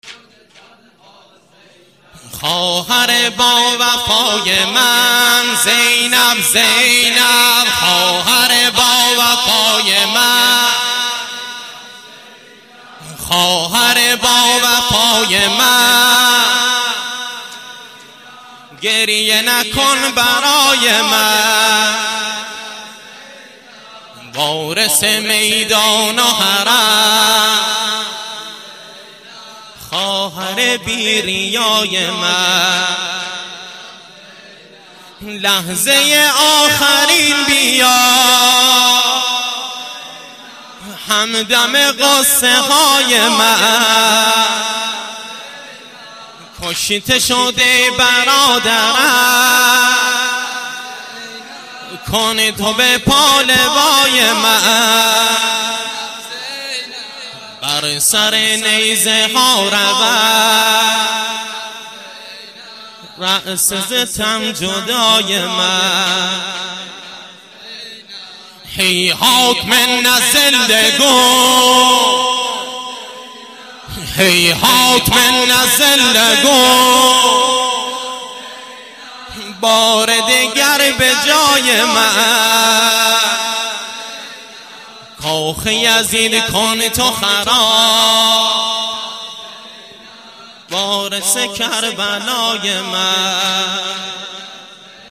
واحد - زینب یا زینب